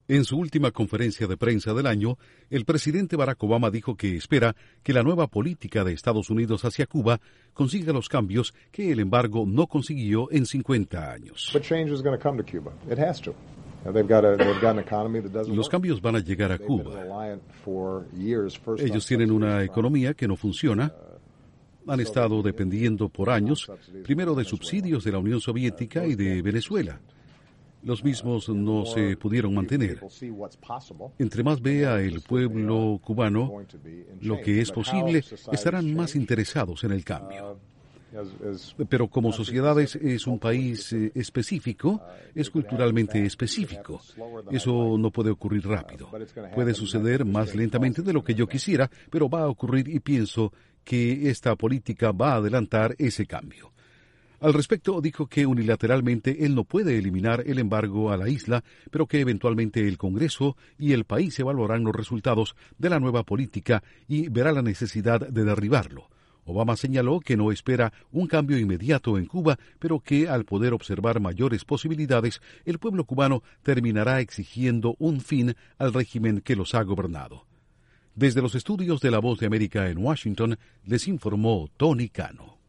Durante su conferencia de prensa anual, el presidente Barack Obama dice que los cambios en Cuba se van dar, aunque poco a poco.